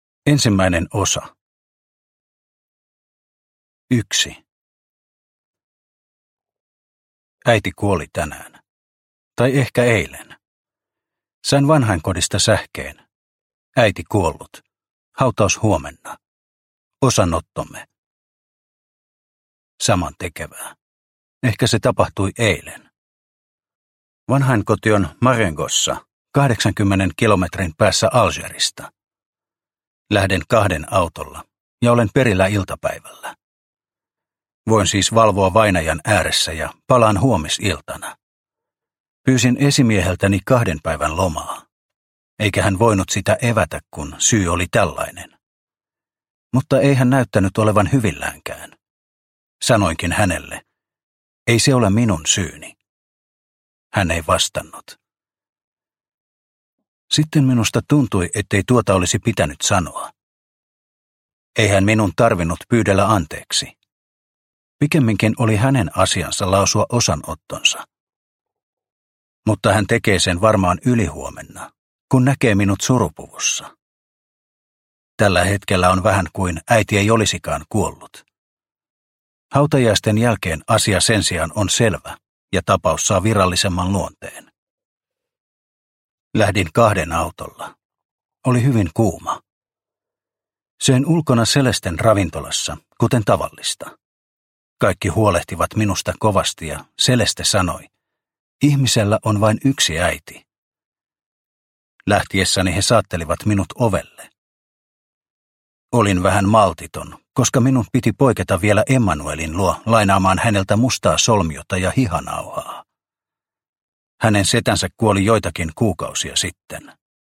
Sivullinen – Ljudbok – Laddas ner